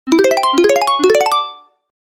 Level Up Success Sound Effect – Winning Notification
Features a bright, uplifting chime with a satisfying reward finish. Perfect for game achievements, level completion, victory screens, winning notifications, app alerts, and UI feedback.
Level-up-success-sound-effect-winning-notification.mp3